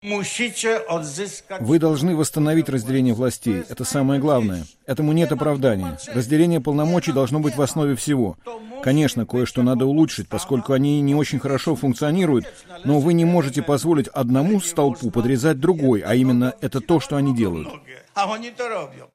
73-летний Валенса выступил на митинге в Гданьске 22 июля и заявил толпе, что следует использовать "все средства" для защиты демократии.